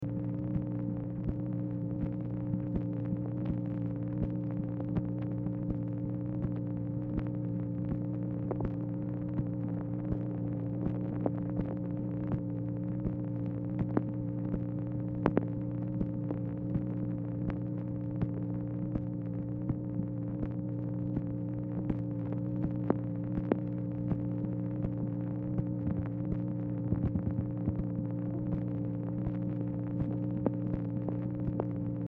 Title Telephone conversation # 6704, sound recording, MACHINE NOISE, 1/4/1965, time unknown Archivist General Note "FR MANS."
Format Dictation belt
Specific Item Type Telephone conversation